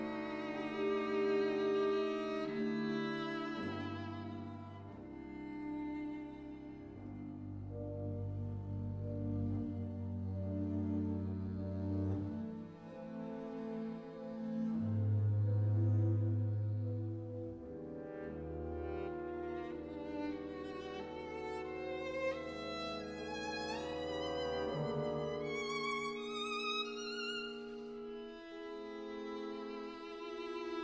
Musique Classique